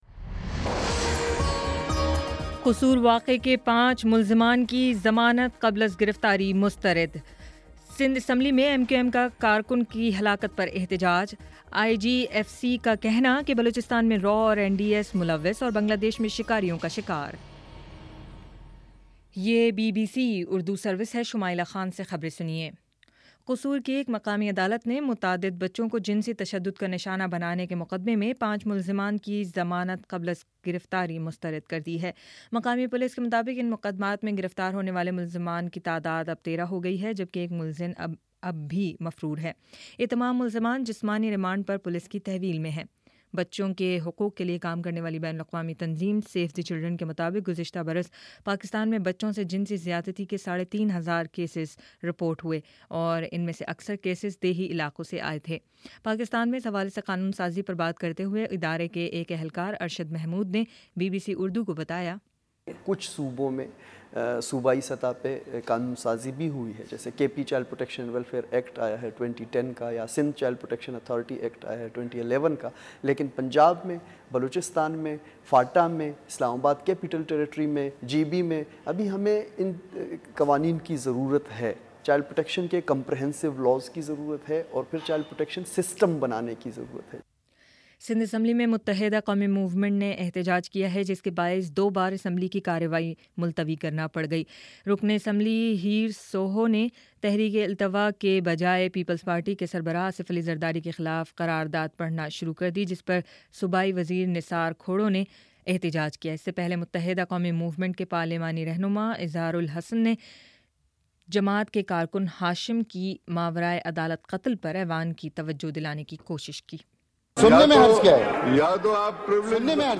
اگست 10: شام چھ بجے کا نیوز بُلیٹن